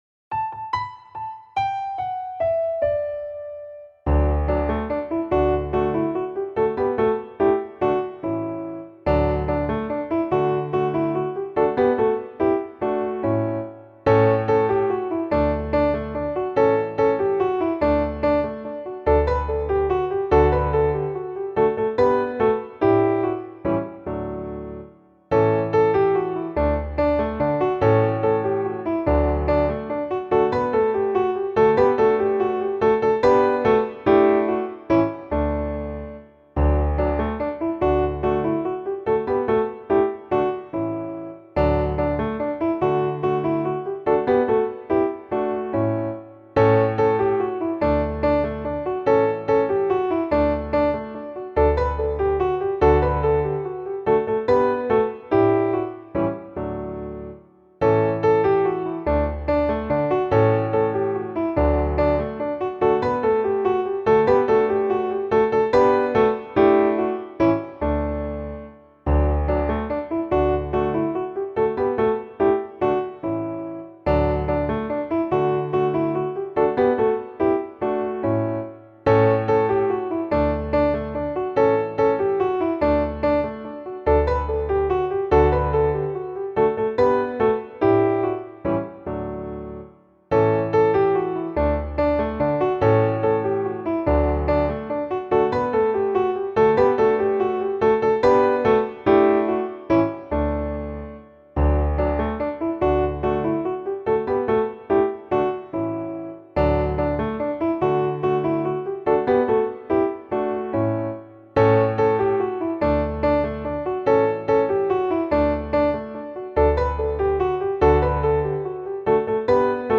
Aby ułatwić przygotowanie się do nagrania, poniżej prezentujemy podkłady muzyczne, w trzech wersjach.